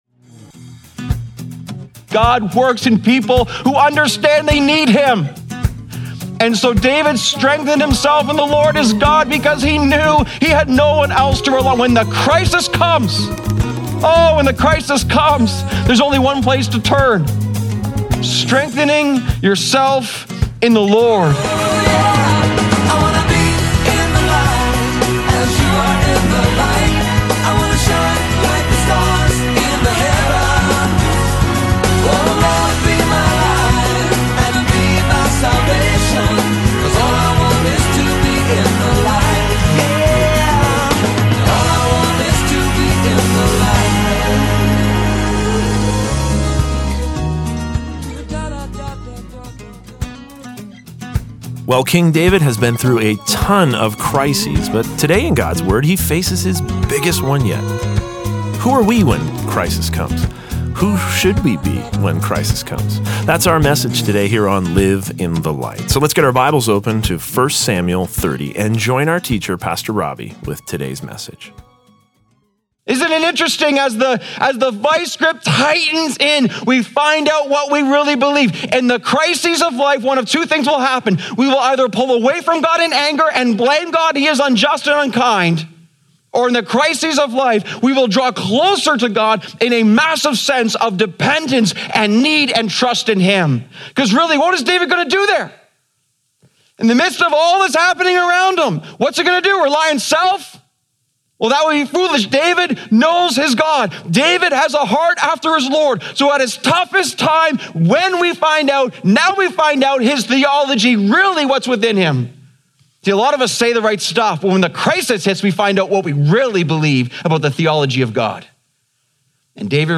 Live in the Light Daily Broadcast When Crisis Comes (Part 2 of 2) Feb 18 2026 | 00:29:30 1x 00:00 / 00:29:30 Subscribe Share Apple Podcasts Spotify Overcast RSS Feed Share Link Embed